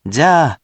We have our computer friend, QUIZBO™, here to read each of the hiragana aloud to you.
#3.) Which hiragana youon do you hear? Hint: 【jya】
In romaji, 「じゃ」 is transliterated as「jya」which sounds like 「jah」